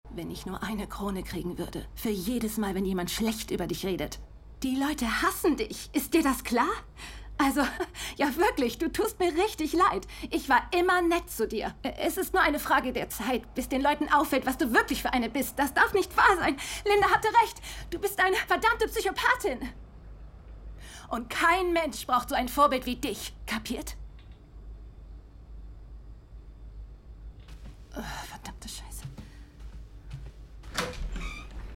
hell, fein, zart, dunkel, sonor, souverän
Mittel minus (25-45)
Lip-Sync (Synchron)